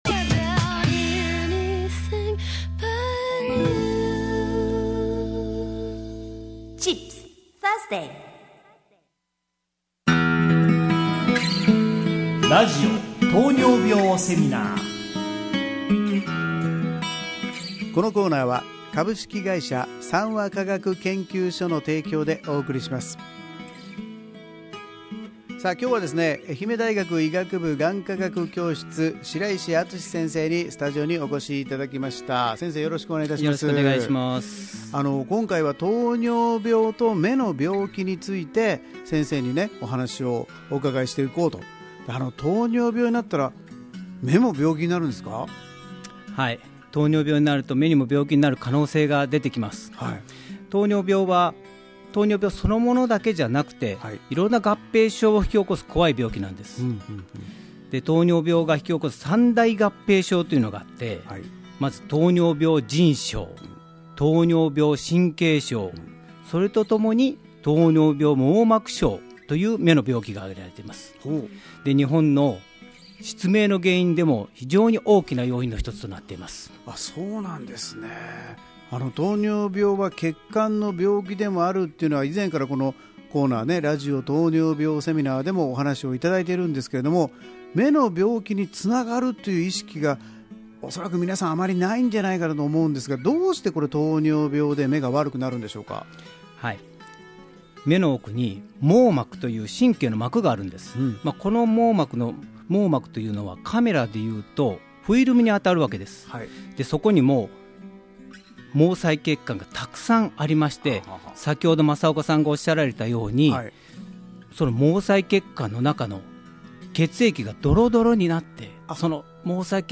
医師